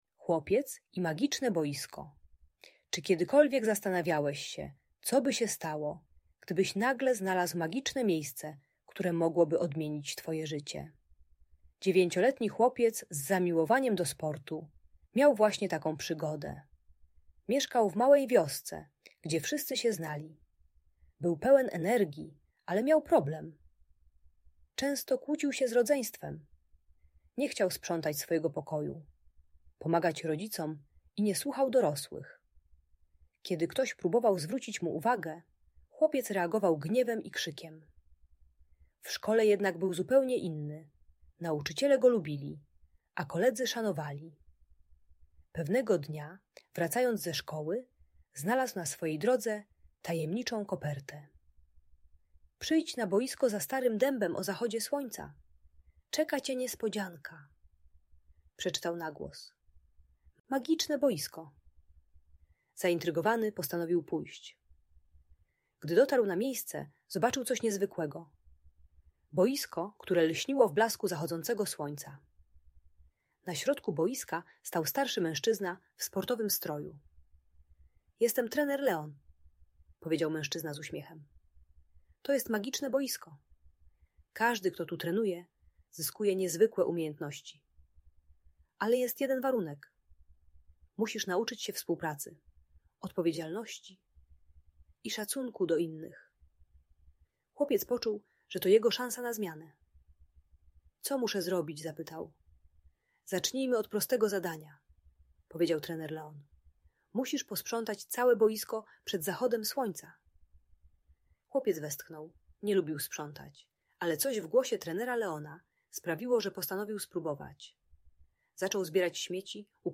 Uczy techniki głębokiego oddechu i wyrażania emocji słowami zamiast krzyku. Audiobajka o złości i szacunku do rodziców.